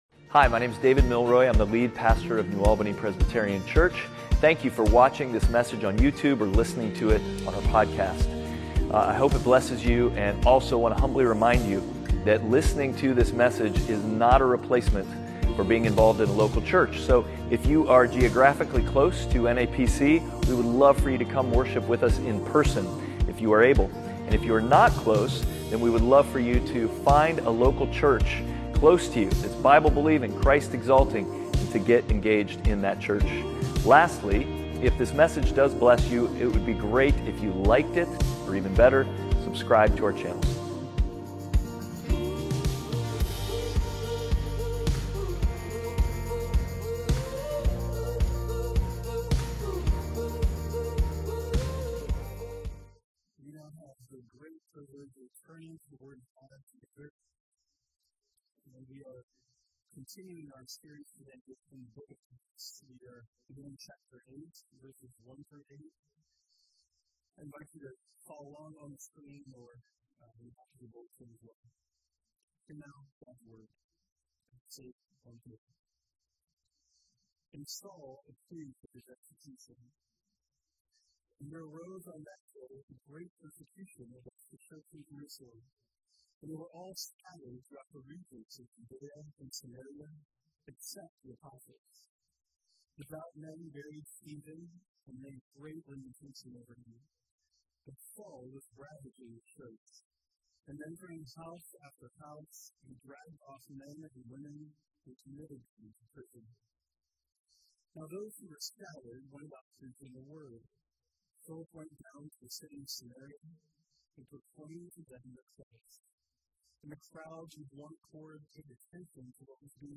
Passage: Acts 8:1-8 Service Type: Sunday Worship